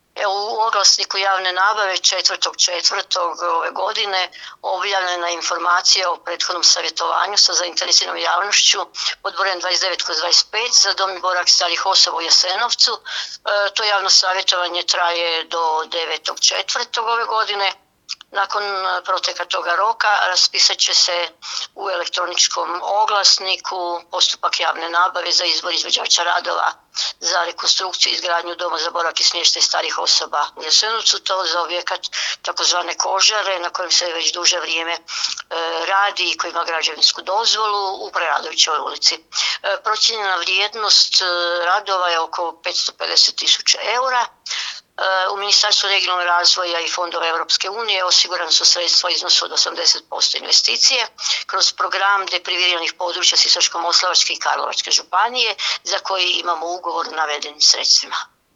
Više o tome, načelnica Marija Mačković